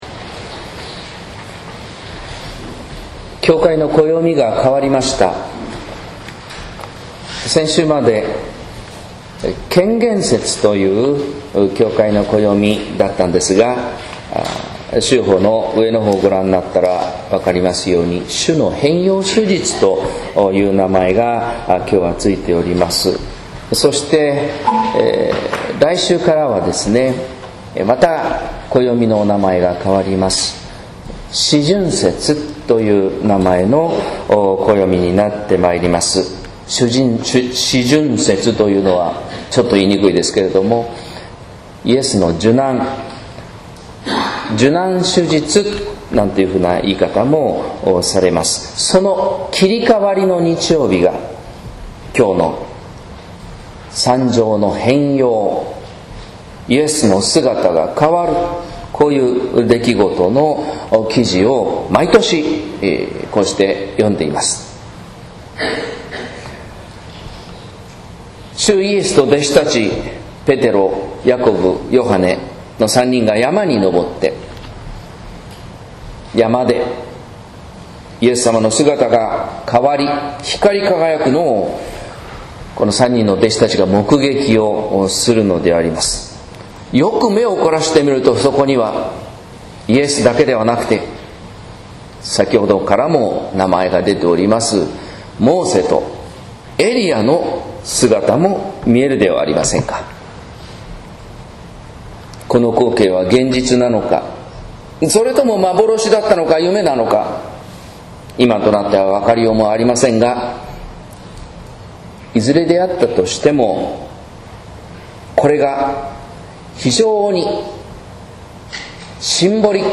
説教「消えないことば」（音声版）